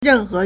任何 rènhé
ren4he2.mp3